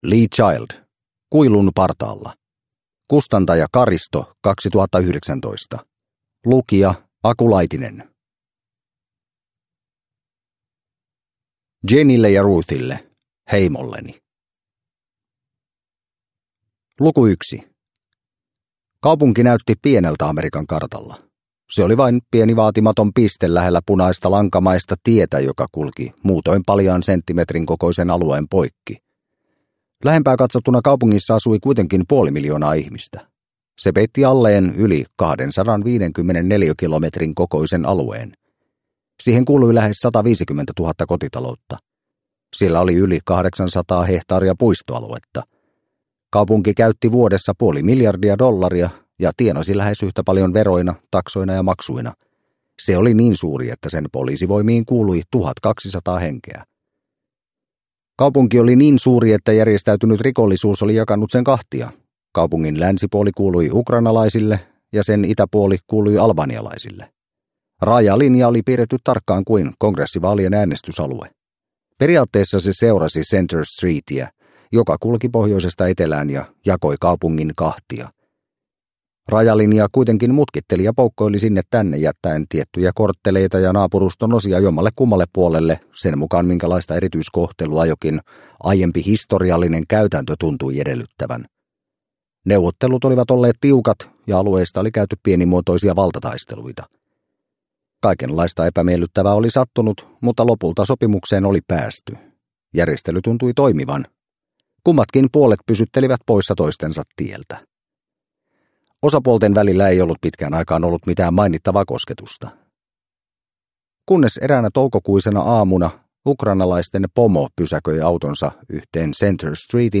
Kuilun partaalla – Ljudbok – Laddas ner